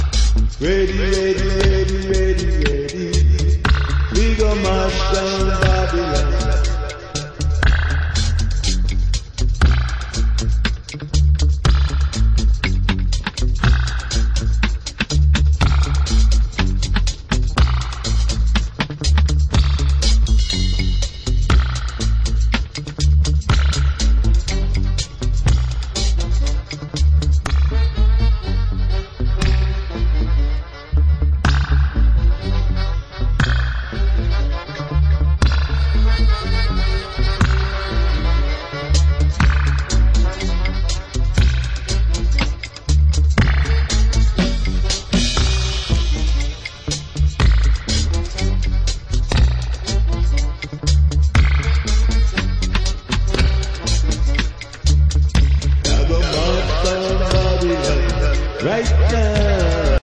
Re issue of ultra rare killer dub album.
Soul Dub Reggae